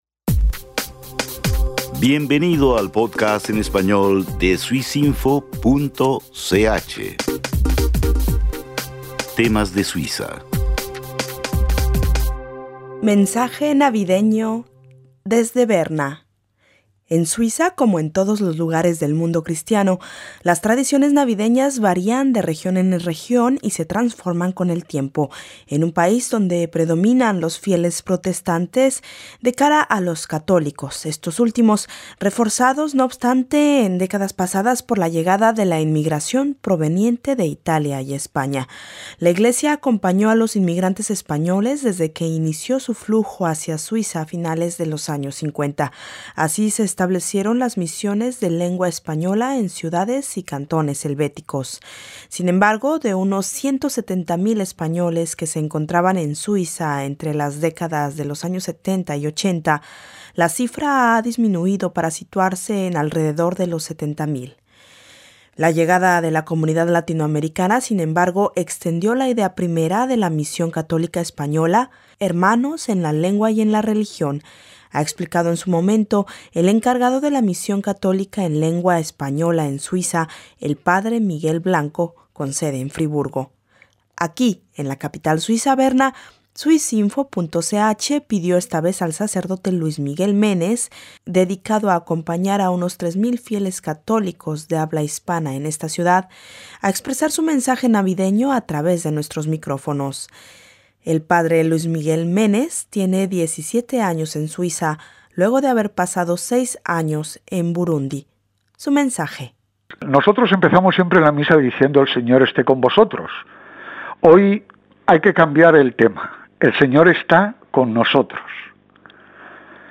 Mensaje navideño